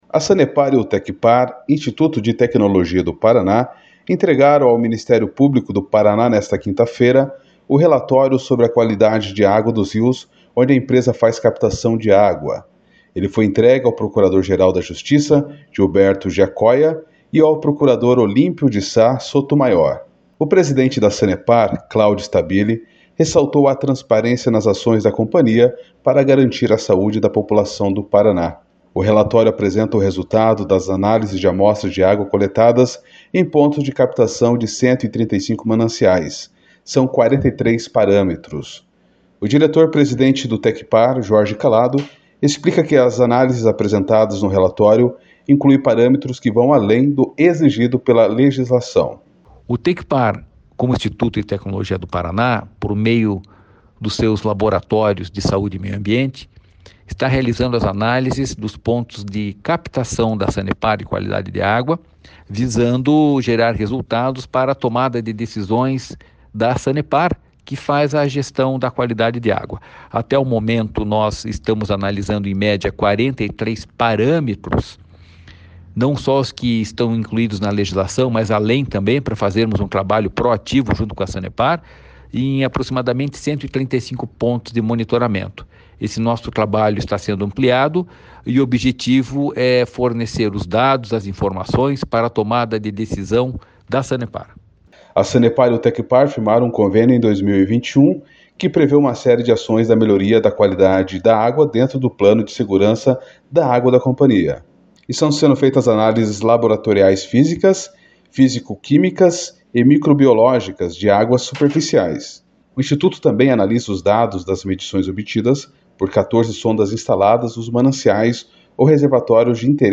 O diretor-presidente do Tecpar, Jorge Callado, explica que as análises apresentadas no relatório incluem parâmetros que vão além do exigido pela legislação.//SONORA JORGE CALLADO//